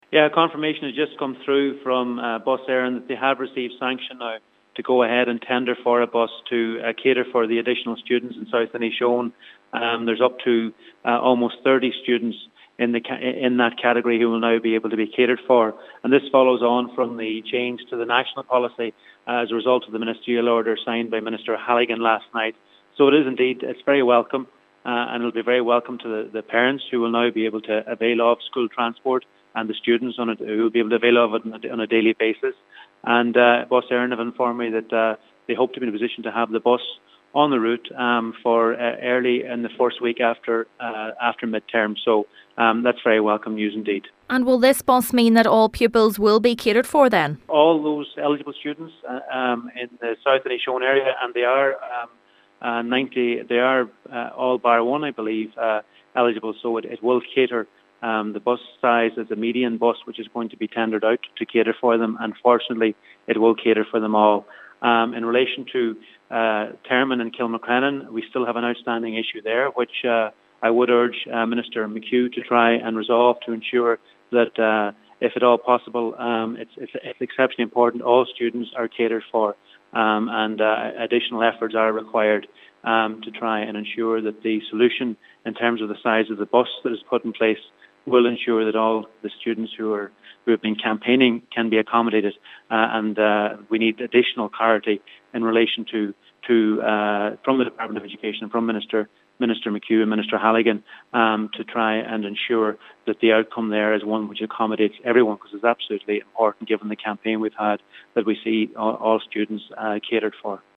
Donegal Deputy Charlie McConalogue says the medium sized bus approved will cater for all pupils: